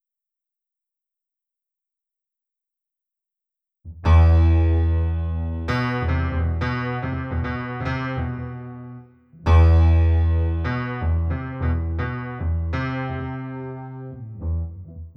hopefully these will help: (low E (left) held while notes are played on the A (right))